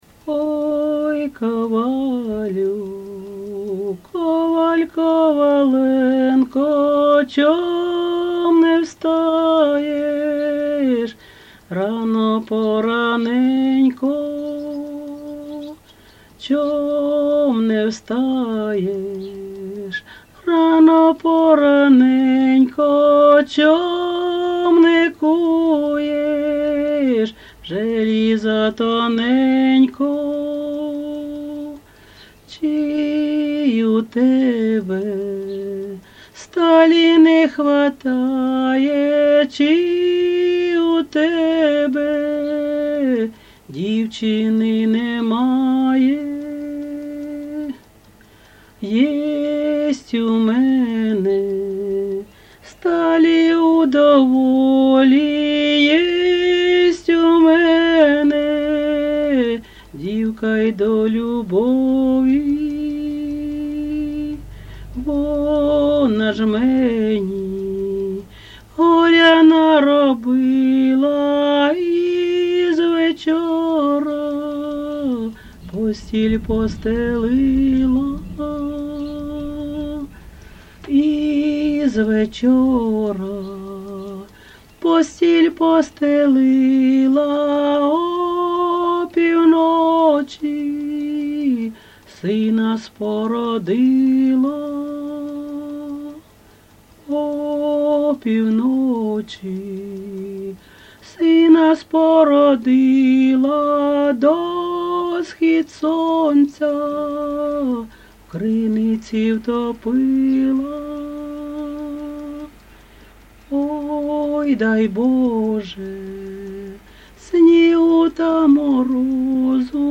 ЖанрПісні з особистого та родинного життя, Балади